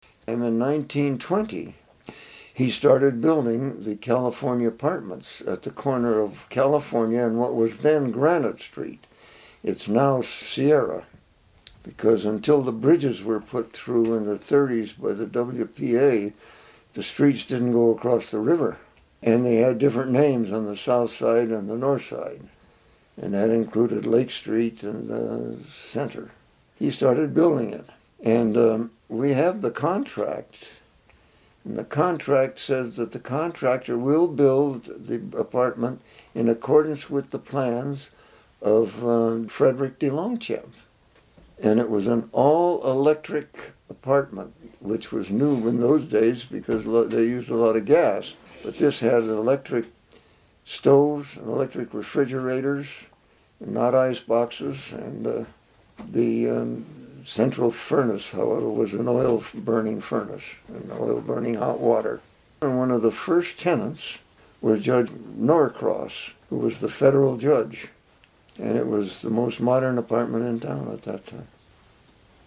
University of Nevada Oral History Program